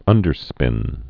(ŭndər-spĭn)